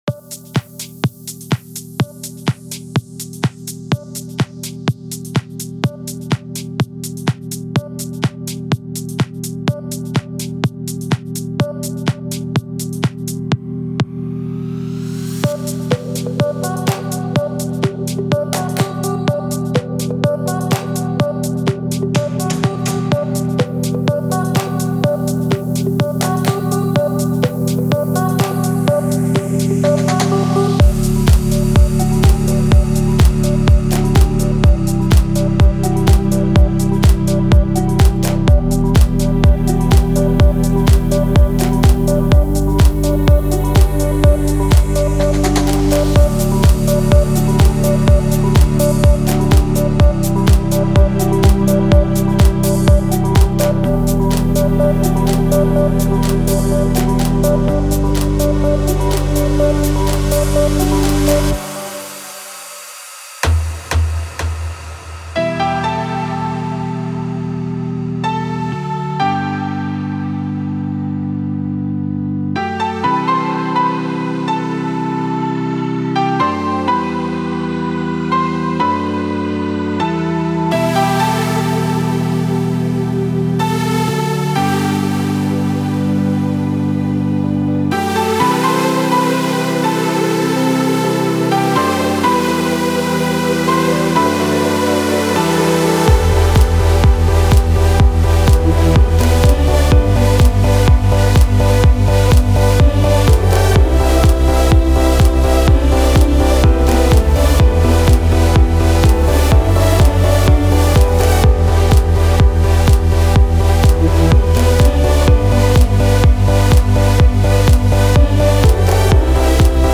Стиль: Deep House / House